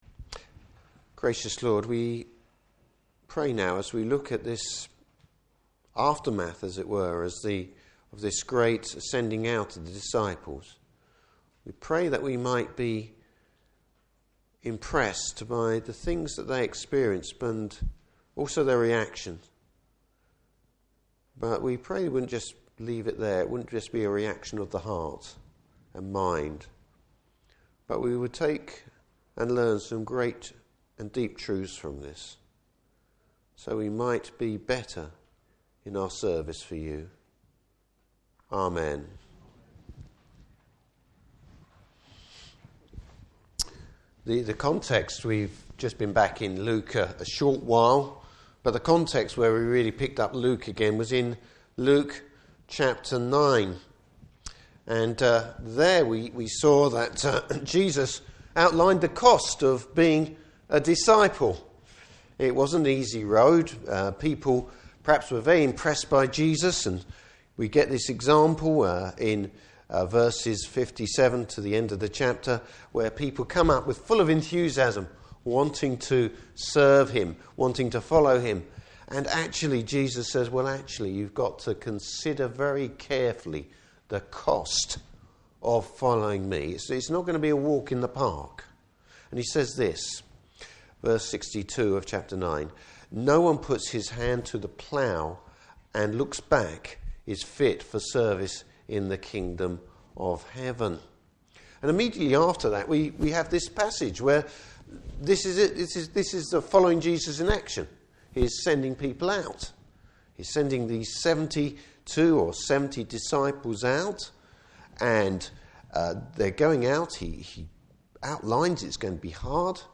Service Type: Morning Service Bible Text: Luke 10:17-24.